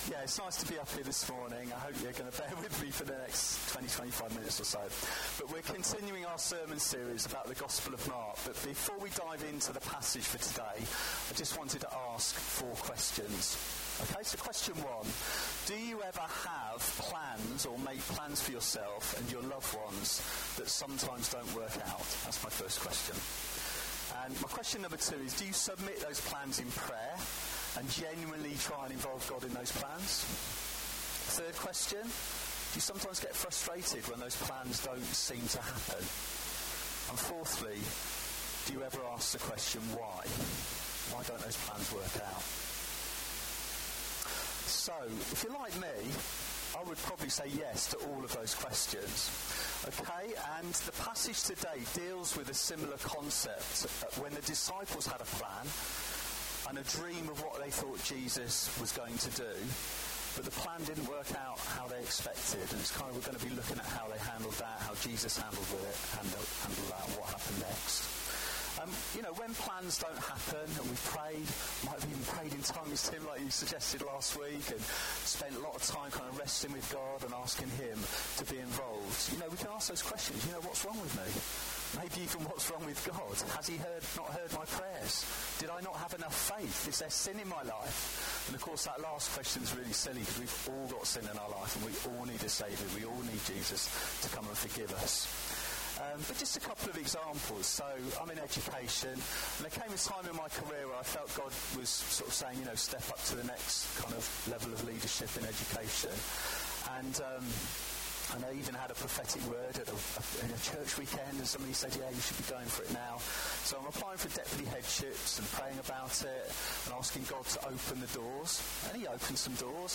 Download Who Am I to You—Prophet, Priest and King? | Sermons at Trinity Church